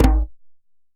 DJEM.HIT15.wav